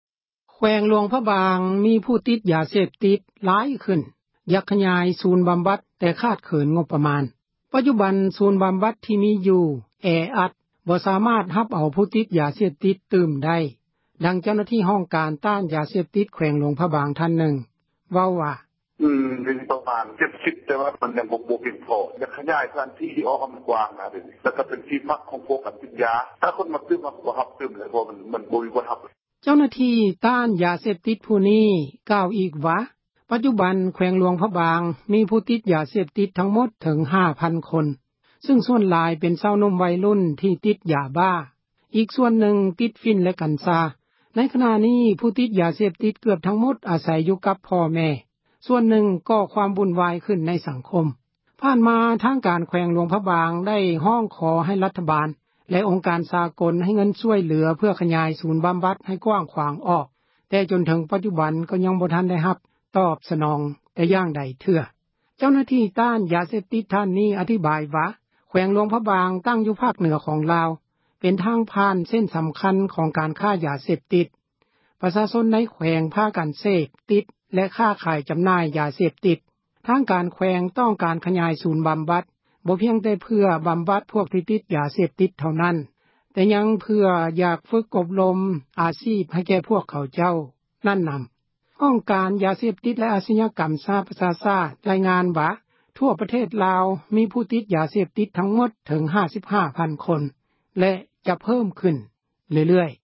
ປັດຈຸບັນ ສູນບໍາບັດ ທີ່ມີຢູ່ ກໍແອອັດ ບໍ່ສາມາດ ຮັບເອົາ ຜູ້ຕິດ ຢາເສບຕິດ ຕື່ມໄດ້, ເຈົ້າໜ້າທີ່ ຫ້ອງການ ຕ້ານ ຢາເສບຕິດ ແຂວງ ຫລວງພຣະບາງ ຜູ້ນຶ່ງ ເວົ້າວ່າ: